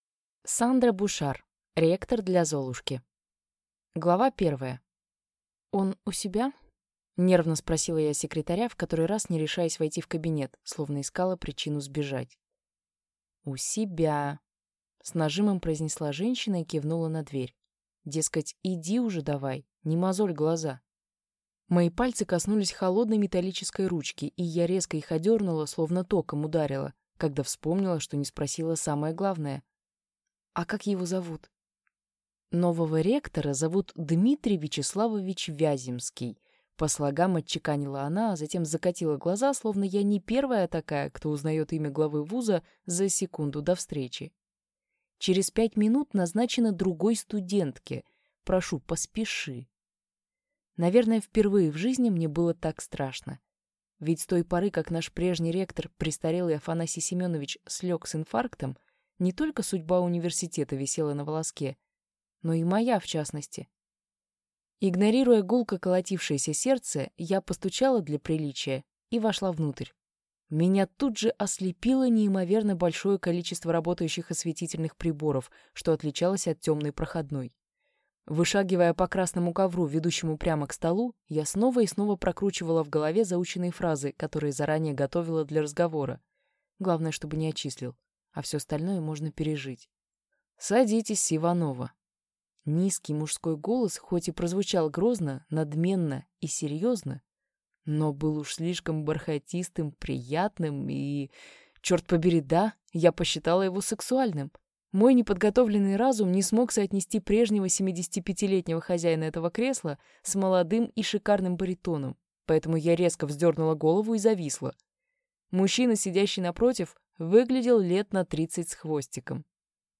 Аудиокнига Ректор для Золушки | Библиотека аудиокниг